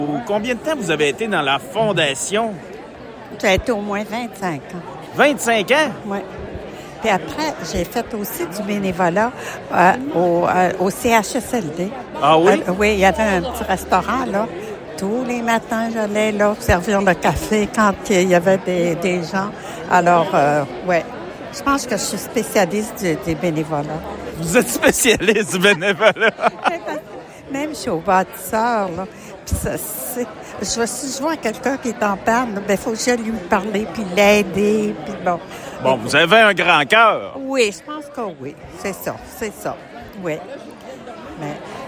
La Fondation de l’Hôpital de La Malbaie a célébré son 40e anniversaire, hier soir, lors d’un cocktail dînatoire au Pavillon Joseph-Rouleau du Domaine Forget.